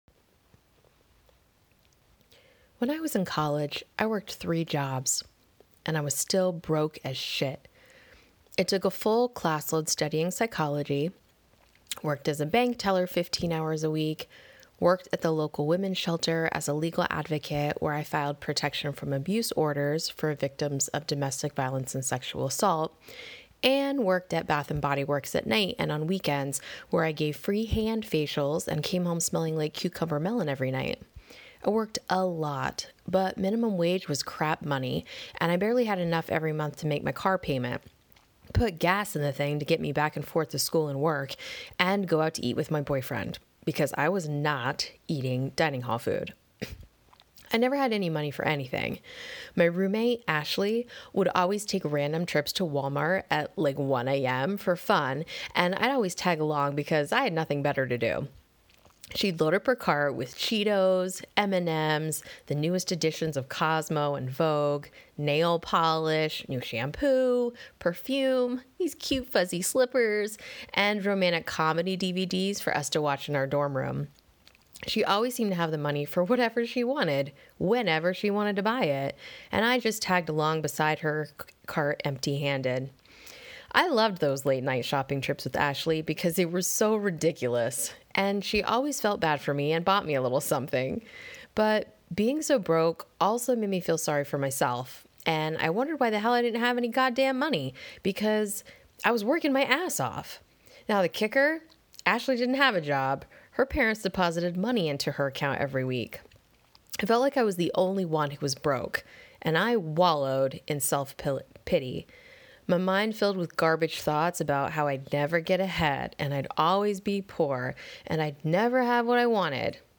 Want me to read this to you?